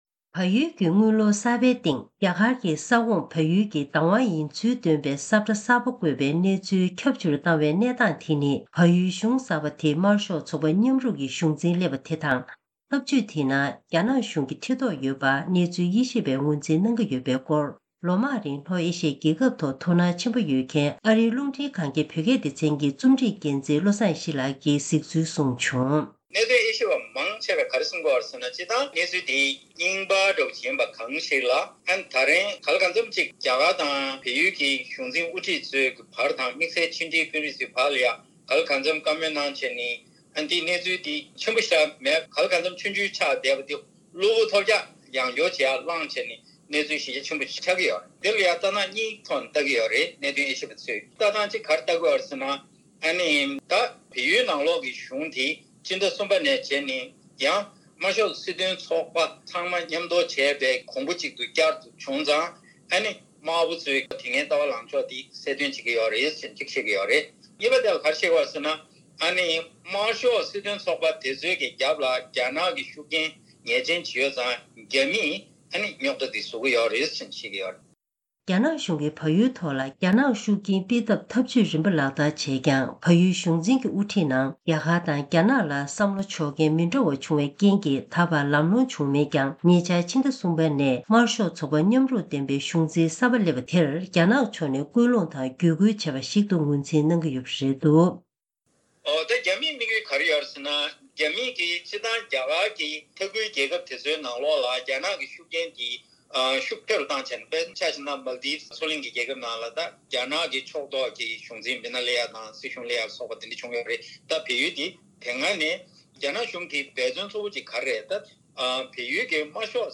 ཐེངས་འདིའི་གསར་འགྱུར་དཔྱད་གཏམ་གྱི་ལེ་ཚན་ནང་།་རྒྱ་གར་གྱི་ས་ཁོངས་ས་ཆ་ཁག་གསུམ་འཁོད་པའི་ས་ཁྲ་གསར་པ་བལ་ཡུལ་གྱི་སྒོར་བརྒྱ་ཐམ་པའི་ཤོག་ལོར་གསར་པའི་སྟེང་དུ་བཀོད་དེ་བལ་ཡུལ་གྱི་ས་ཁོངས་བདག་དབང་ཡིན་ལུགས་རྣམ་འགྱུར་བཏོན་པ་དེའི་བྱ་སྤྱོད་ནང་རྒྱ་ནག་གཞུང་གི་ཐེ་གཏོགས་ཡོད་པ་ངོས་འཛིན་བྱེད་ཀྱི་ཡོད་པ་དེའི་དམིགས་ཡུལ་སོགས་ཀྱི་སྐོར་བཀའ་འདྲི་ཞུས་པ་ཞིག་གསན་རོགས་གནང་།